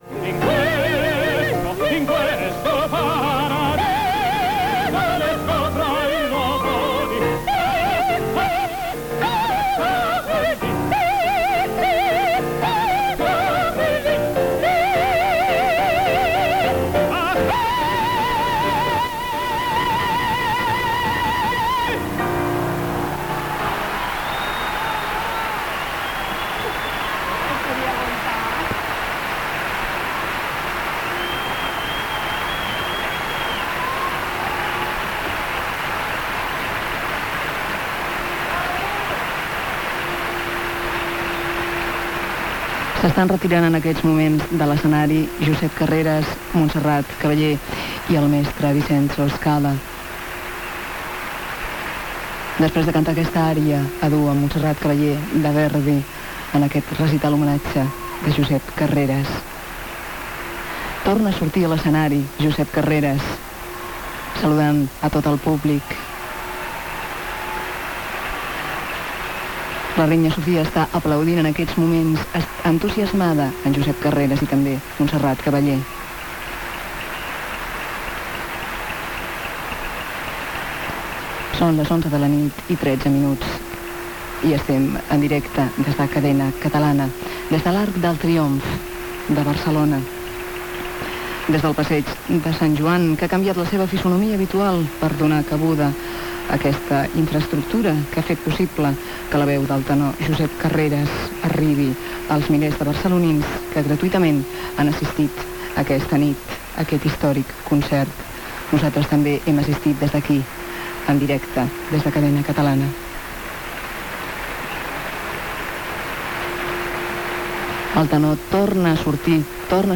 Transmissió. des de l'Arc de Triomf de Barcelona. del recital "Tèspero a l'Arc de Triomf". Final del duet de Josep Carreras i Montserrat Caballé cantant "Libiamo ne' lieti calici" de "La traviata" de Giuseppe Verdi. Narració del final del recital amb identificació de l'emissora
Musical